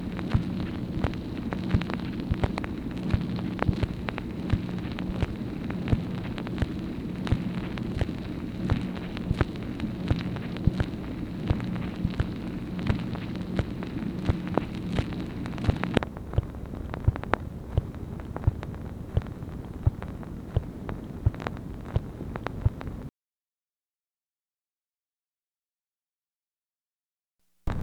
MACHINE NOISE, March 2, 1964
Secret White House Tapes